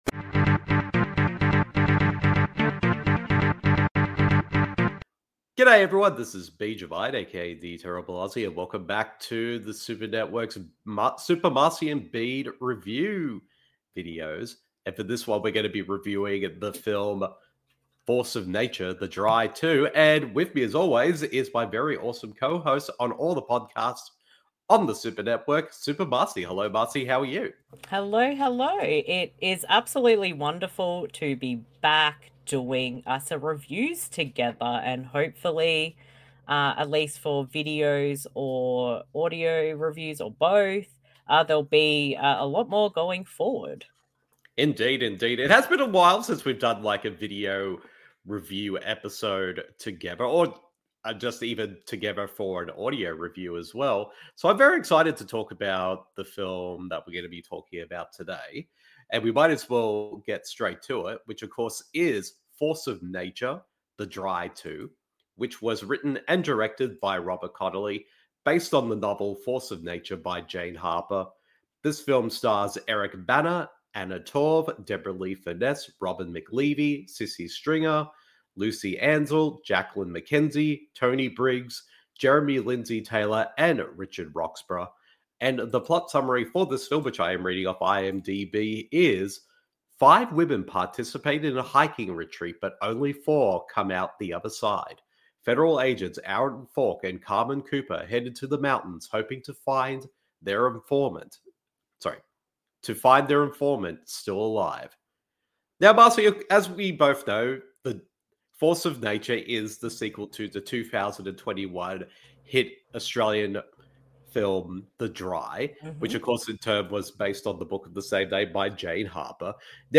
Video/Audio Review